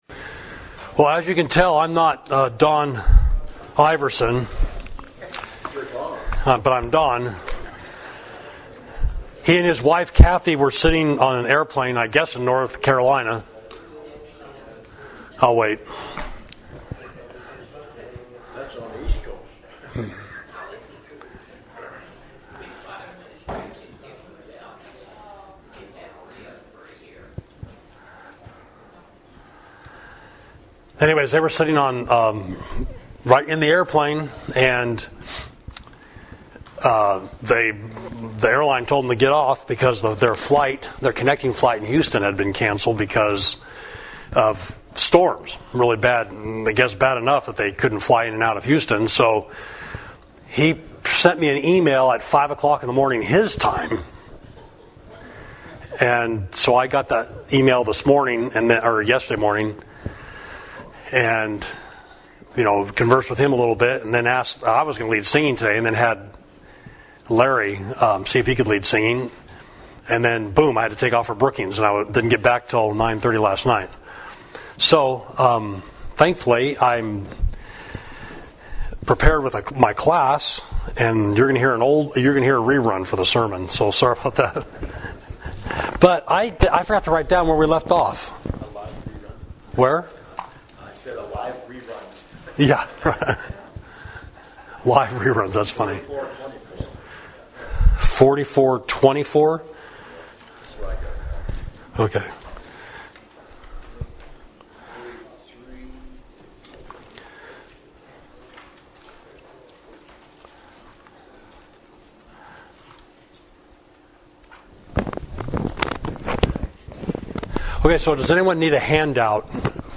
Class: Outline of the Book of Isaiah